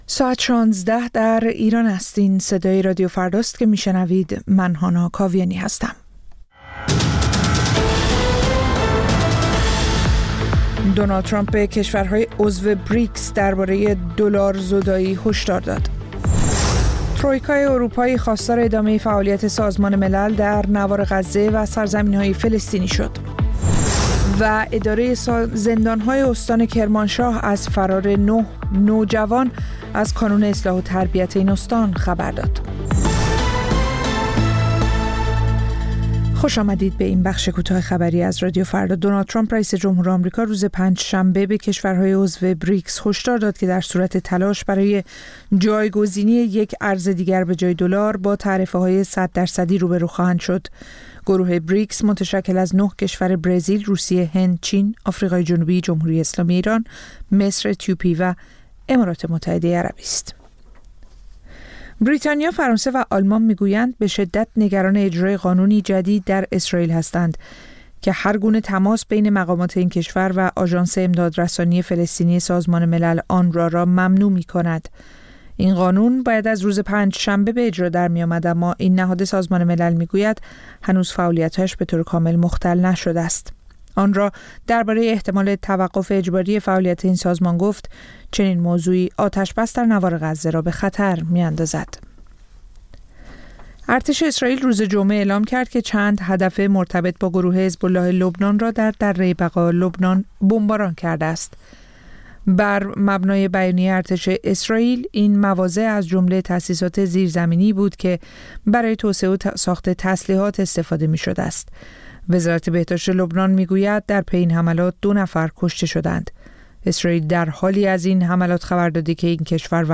سرخط خبرها ۱۶:۰۰